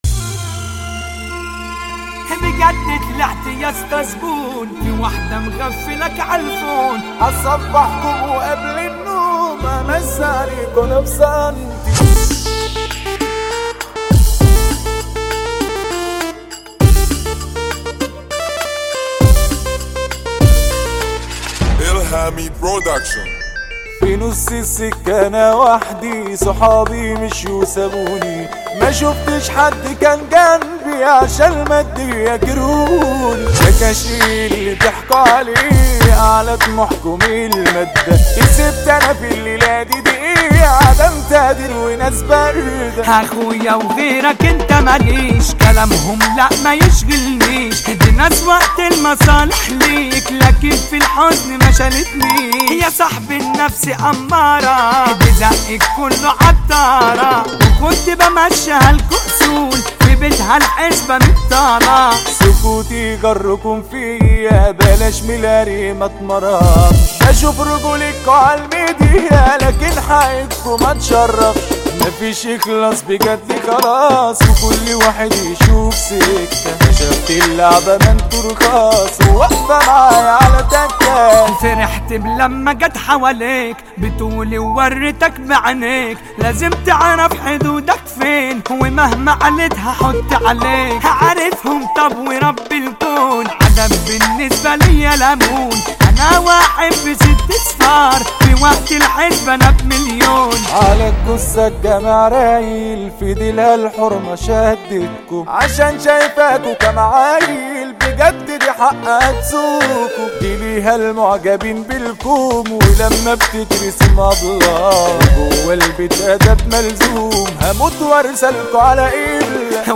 مهرجانات جديدة